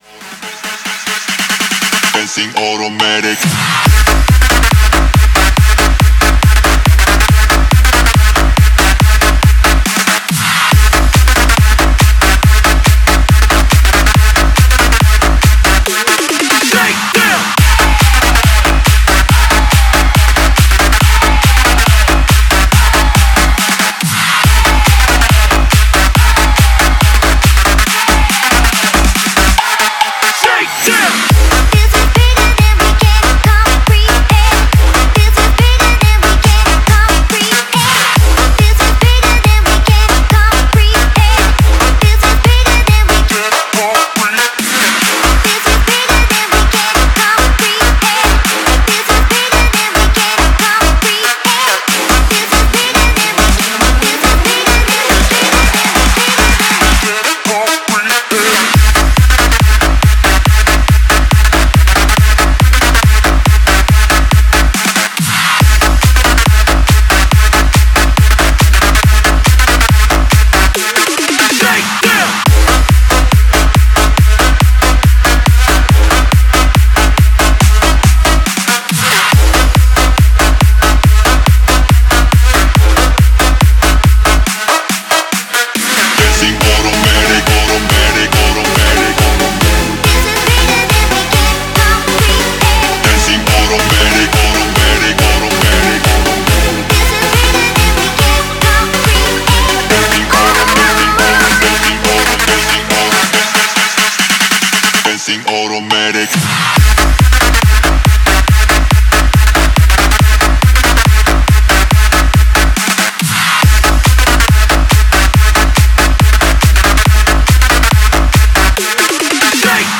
136-140 bpm: 2 min